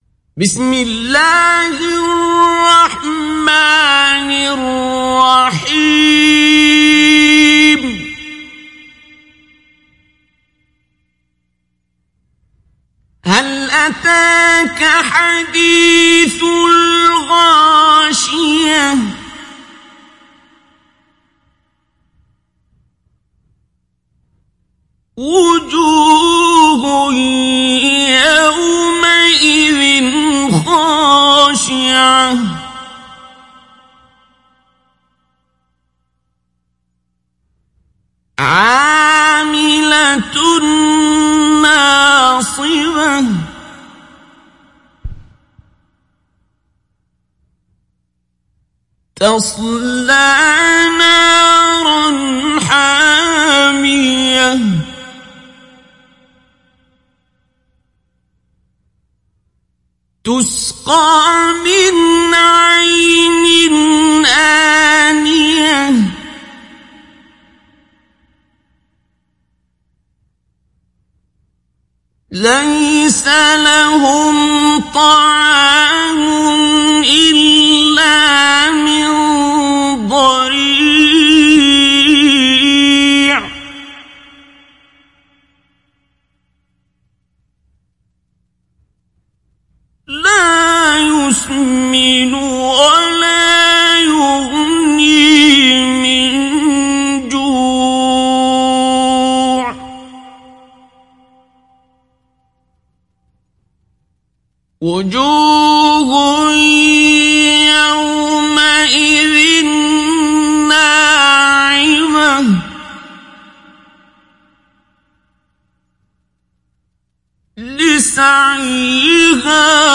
Download Surah Al Ghashiyah Abdul Basit Abd Alsamad Mujawwad